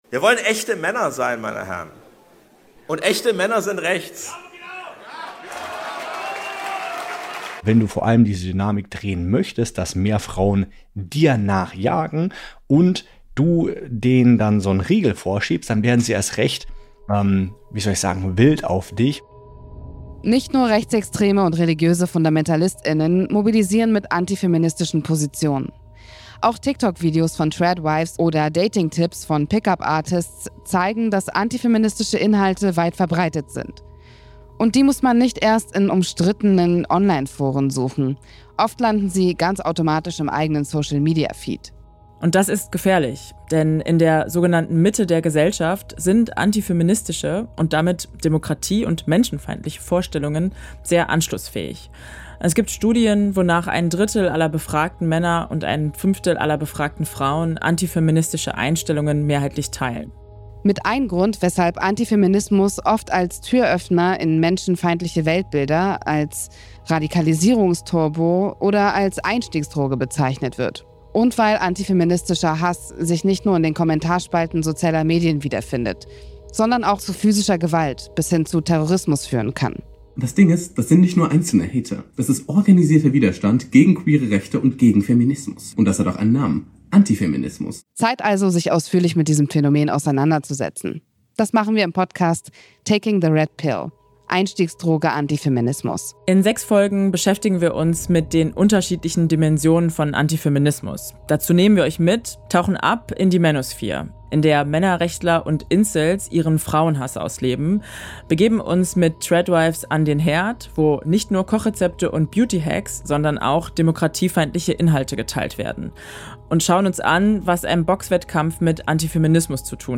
• Postproduktion und Sounddesign: Tonik Studio GmbH